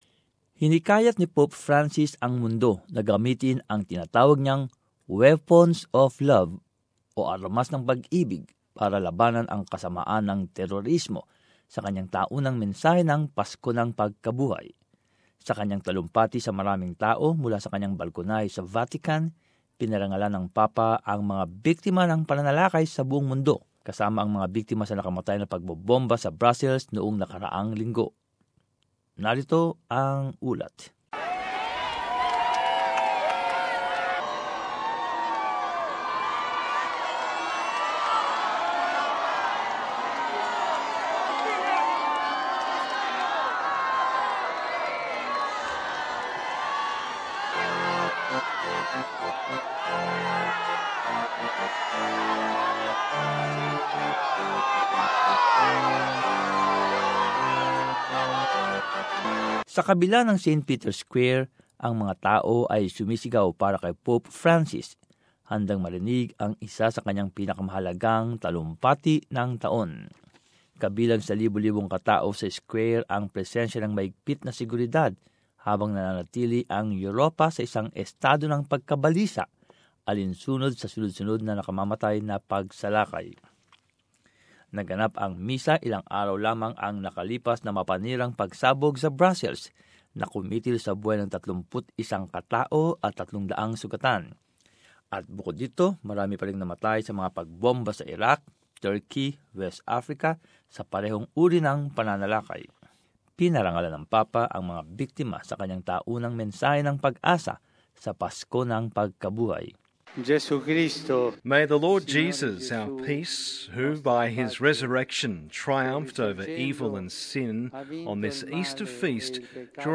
Speaking to a large audience from his balcony at the Vatican, the Pontiff has paid tribute to the victims of attacks worldwide, including last week's deadly bombings in Brussels.